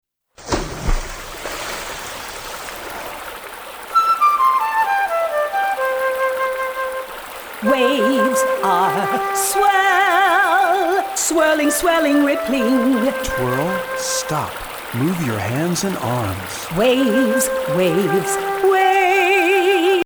calming and alerting songs
CALMING SONGS ALERTING SONGS